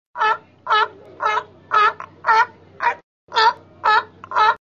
В коллекции — рычание, кряхтение и другие характерные голосовые реакции этих морских животных.
Звук морского тюленя